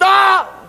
TM88 StopVox.wav